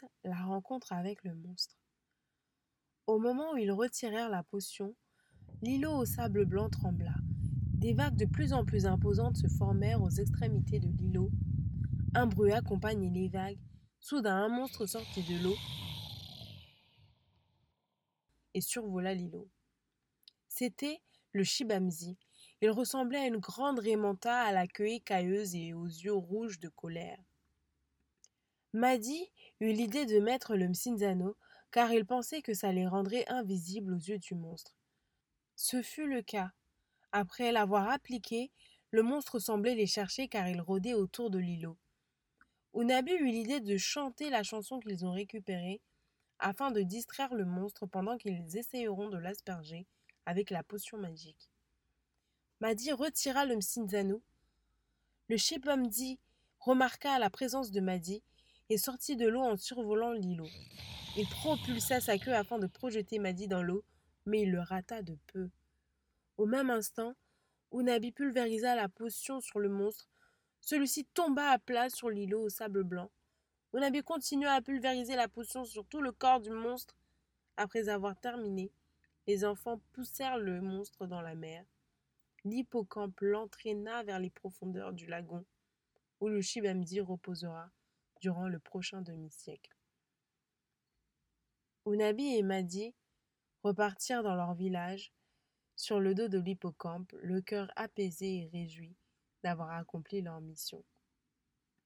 2021 - Album audio jeunesse - Tradition orale conte et légende